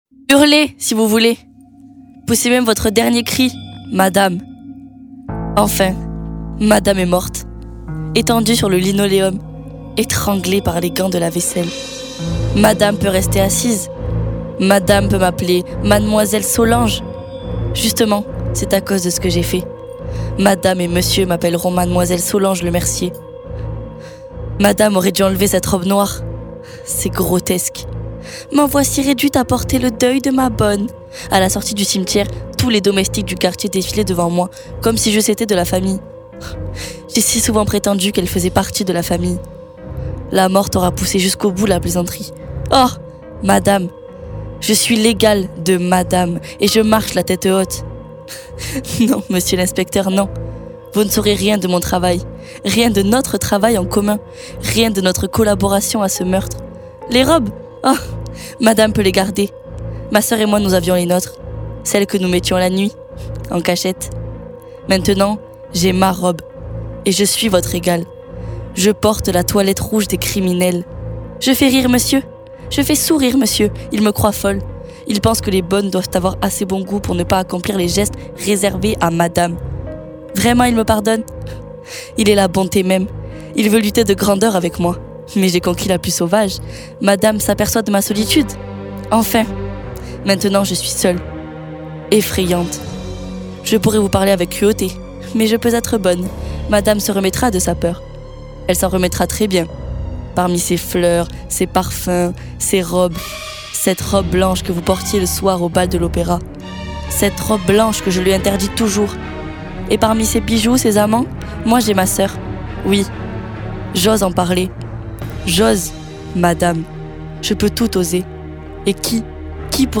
Ateliers de création radiophonique